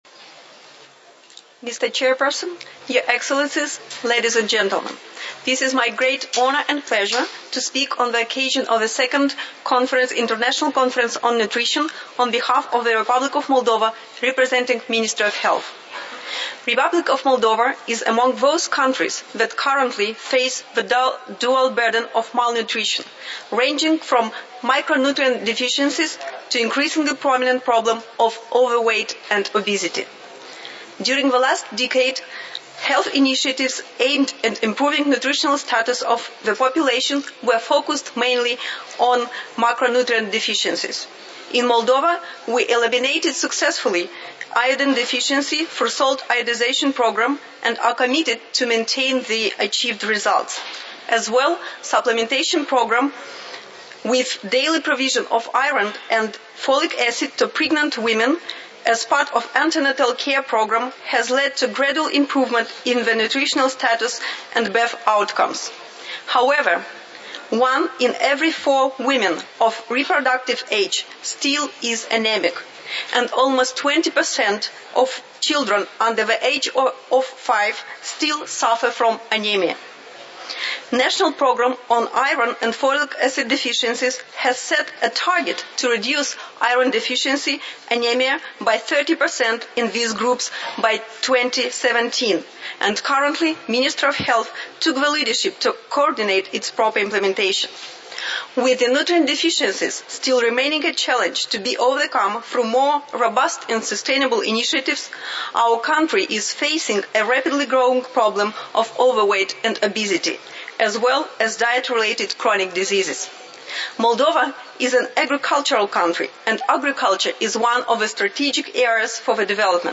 Discursos y declaraciones